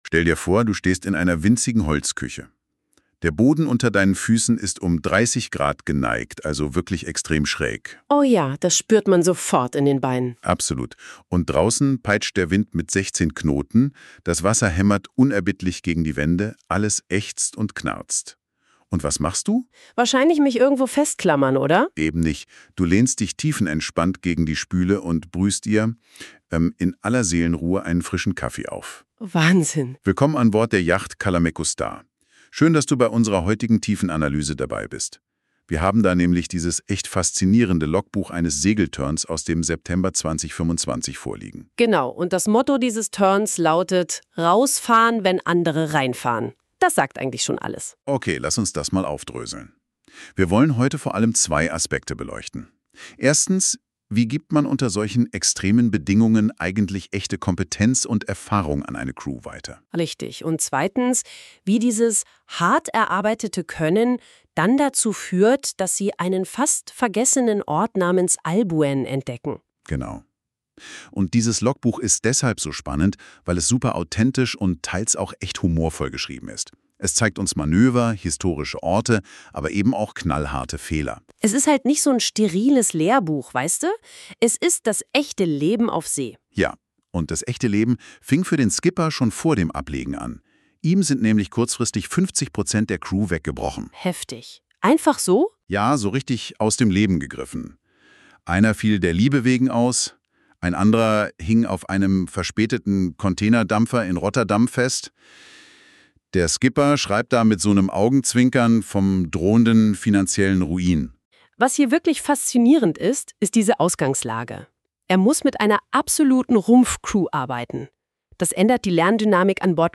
wenn andere reinfahr'n. Zum ambitionierten Törn das Logbuch: und dazu ein von NotebookLM erzeugter Podcast, klickstu hier ...